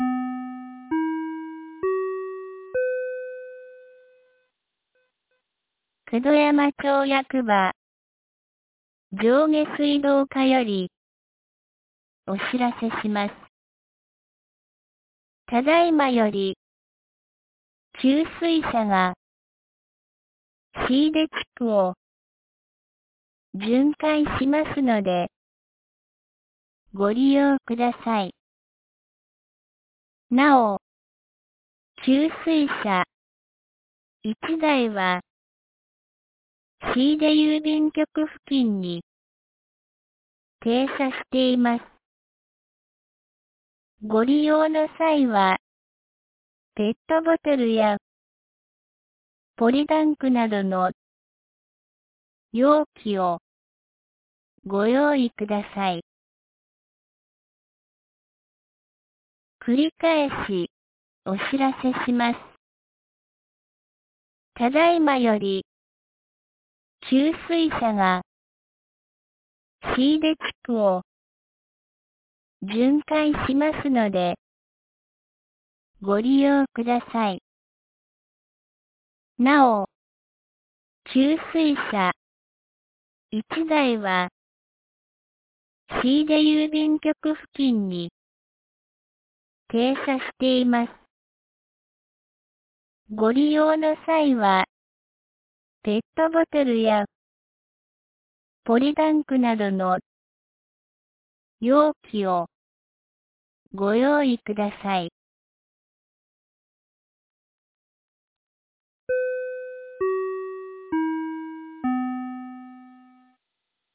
2023年06月03日 12時22分に、九度山町より椎出地区へ放送がありました。
放送音声